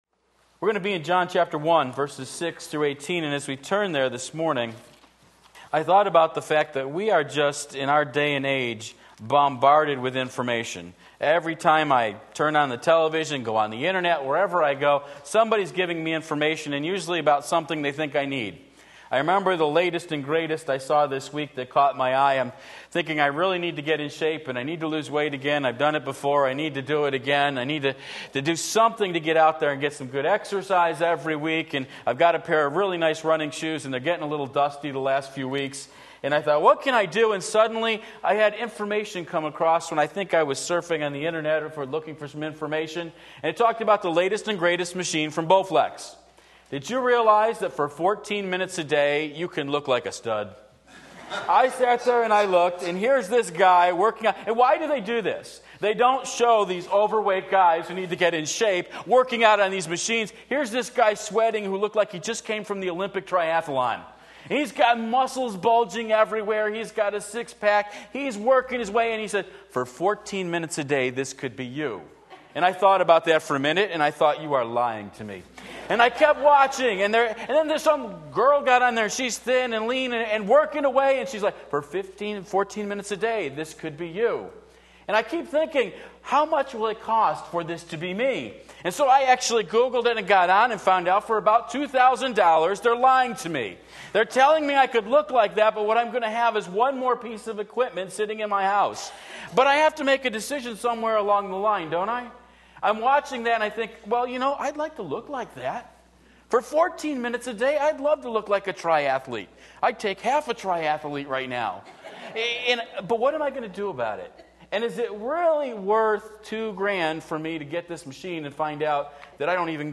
Sermon Link
John 1:6-18 Sunday Morning Service, October 30, 2016 Believe and Live!